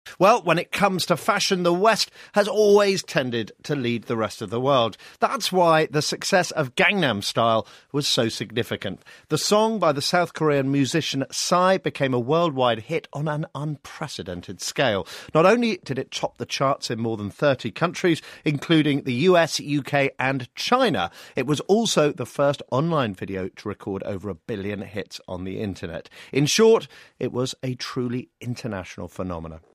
【英音模仿秀】江南出风格 韩潮更袭人 听力文件下载—在线英语听力室